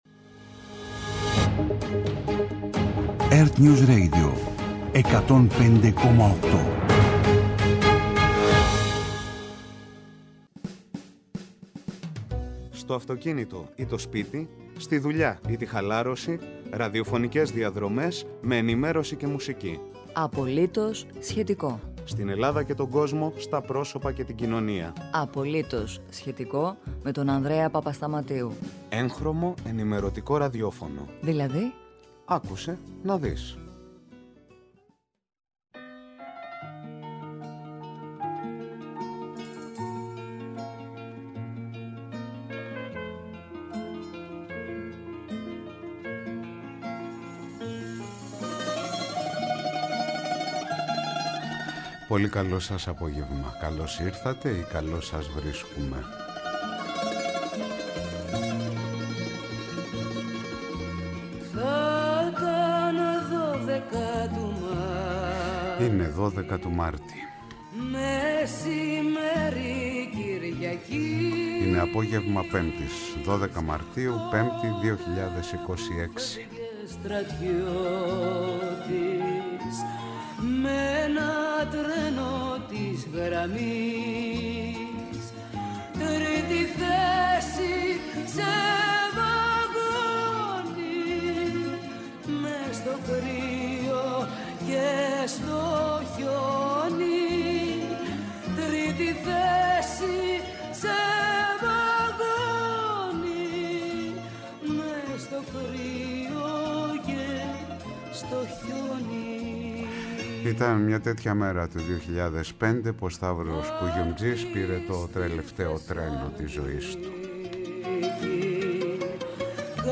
στην αθλητική ενημέρωση